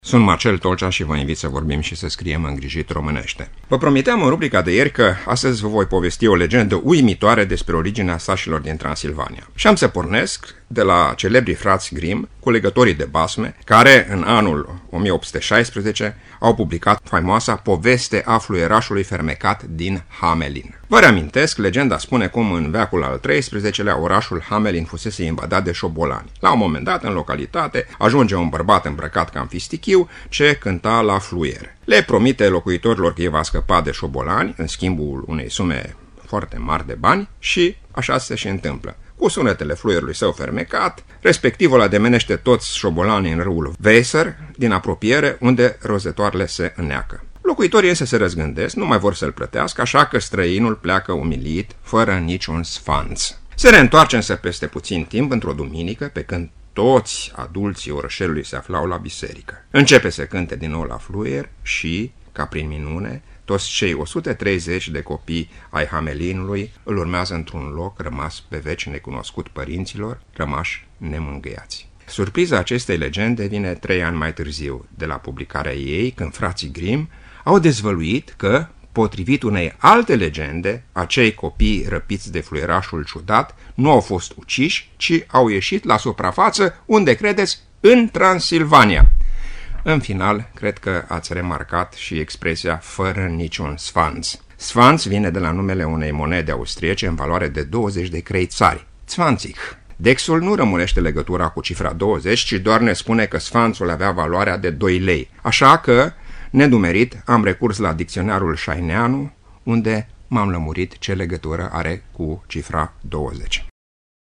(rubrică difuzată în 29 iunie 2017)